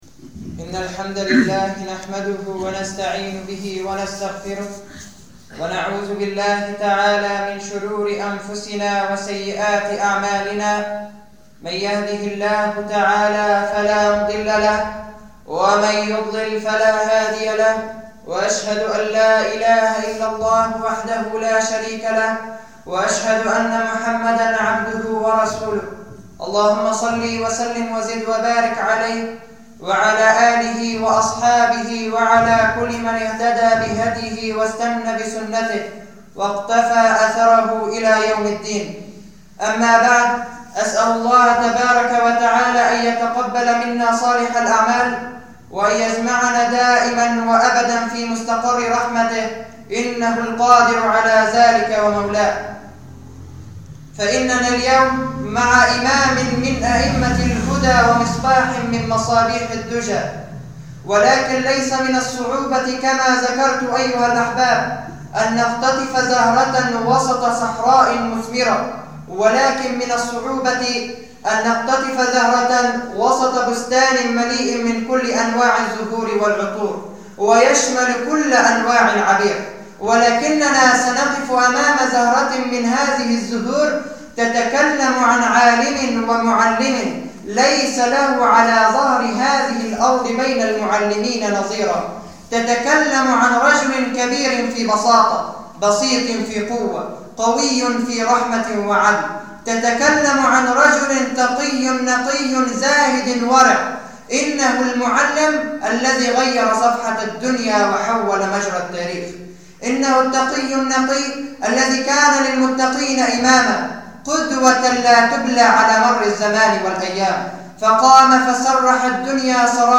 [خطبة جمعة] عمر بن الخطّاب رضي الله عنه